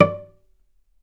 healing-soundscapes/Sound Banks/HSS_OP_Pack/Strings/cello/pizz/vc_pz-D5-ff.AIF at b3491bb4d8ce6d21e289ff40adc3c6f654cc89a0
vc_pz-D5-ff.AIF